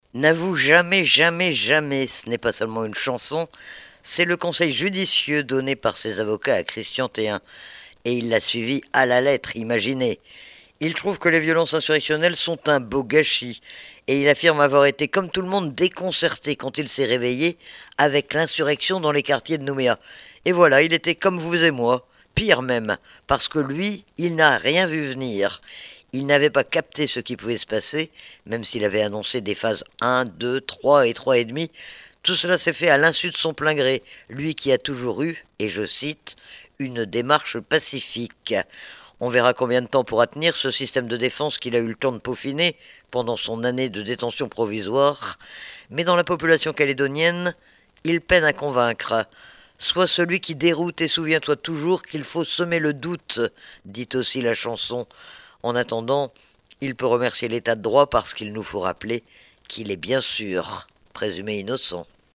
LE BILLET D'HUMEUR